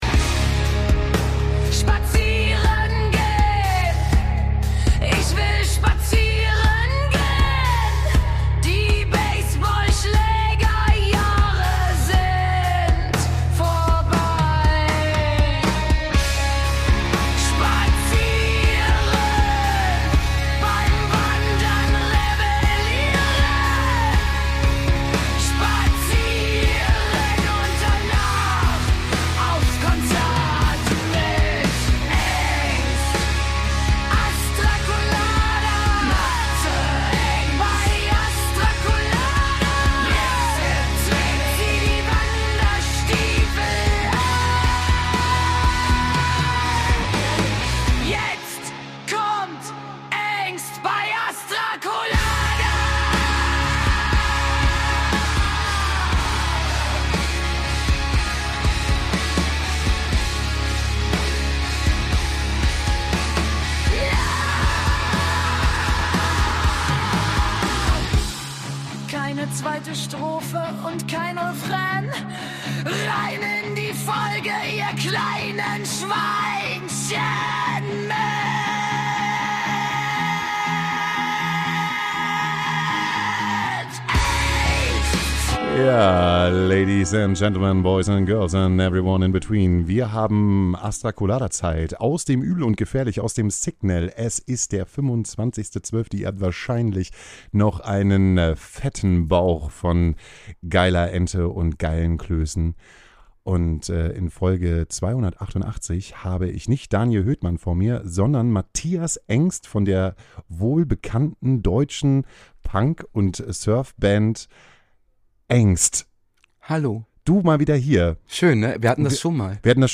in den heiligen Hallen des Uebel & Gefährlich